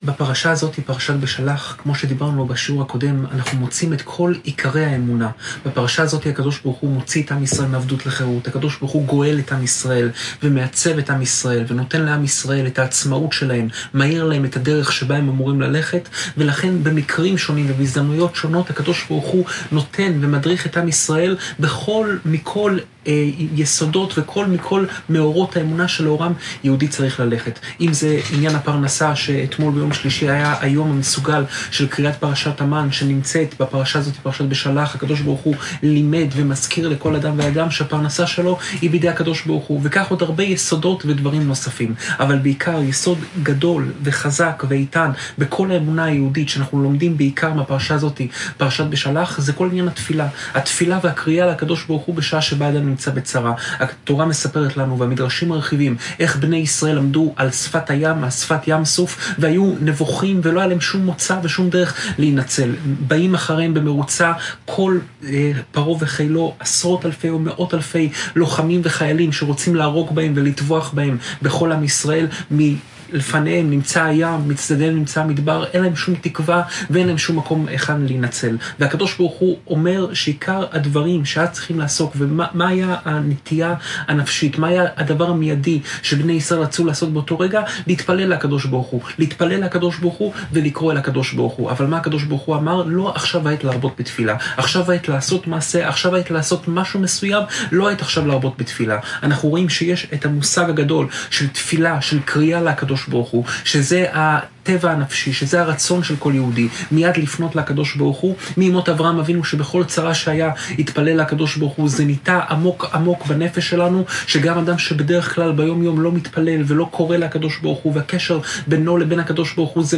שעורי תורה מפי קדשו של הרב יאשיהו יוסף פינטו
שעורי תורה מפי הרב יאשיהו יוסף פינטו